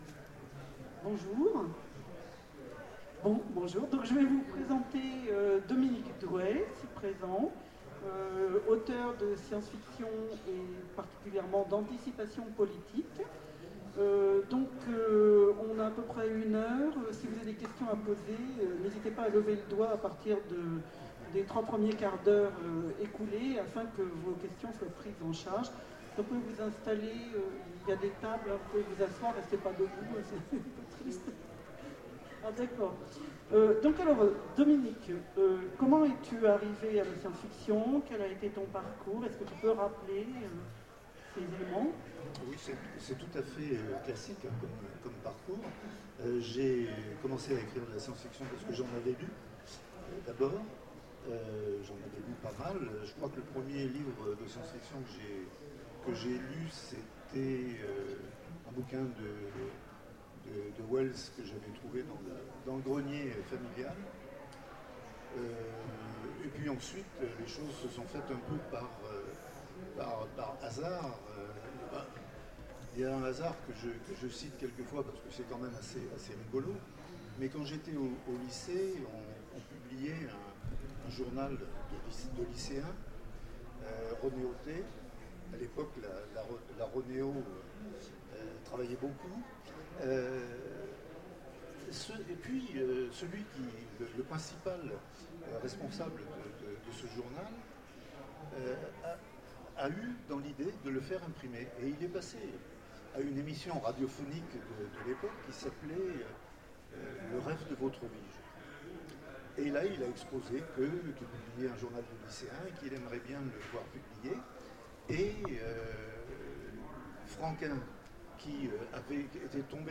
Mots-clés Rencontre avec un auteur Conférence Partager cet article